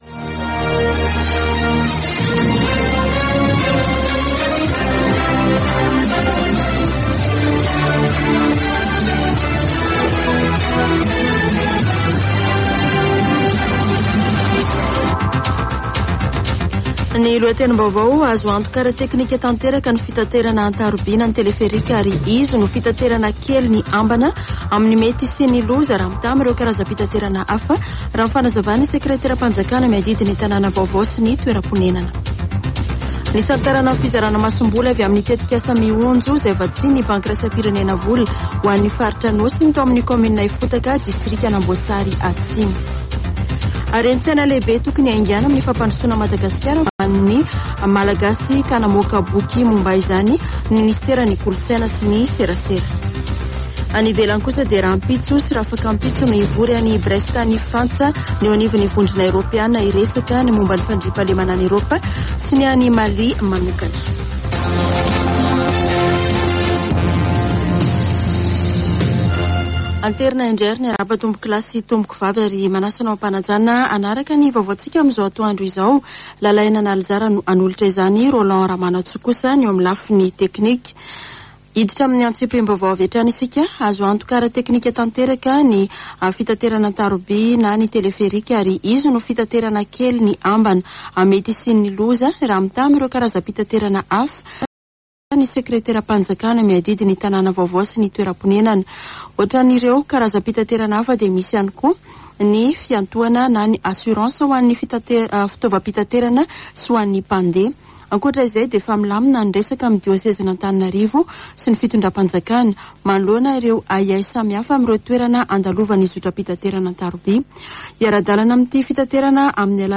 [Vaovao antoandro] Alarobia 12 janoary 2022